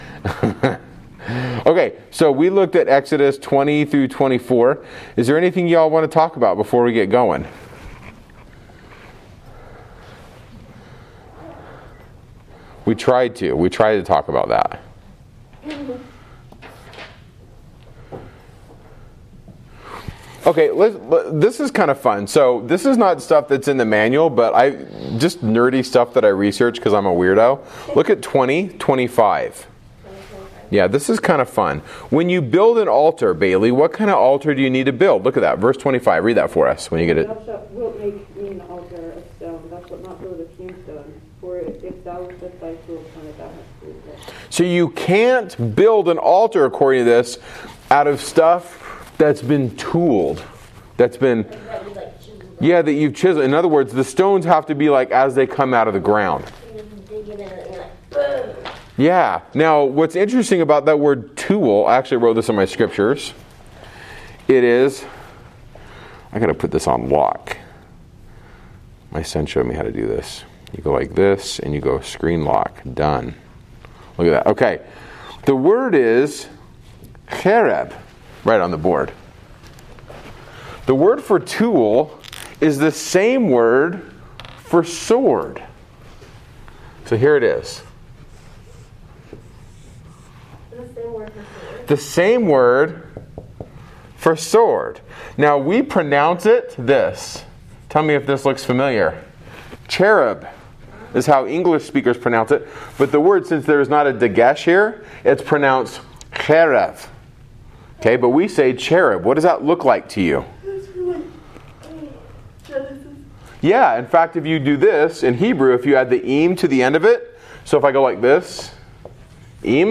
Exodus 15-17 The Song of the Sea & Amalek – live class recording 11.12.20